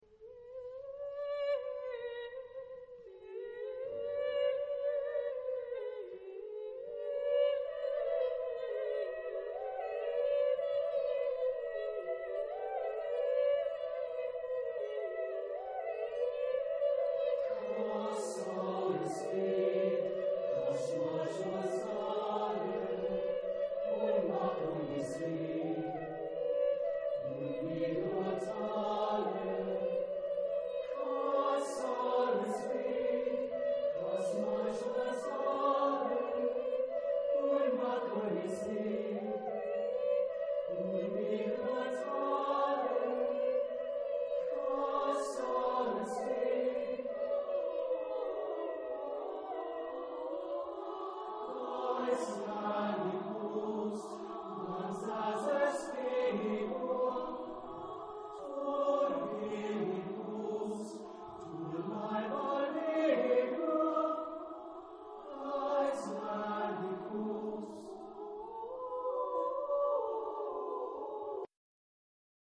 Genre-Style-Forme : Chœur ; Profane ; Poème
Type de choeur : SSSSAAAATB  (10 voix mixtes )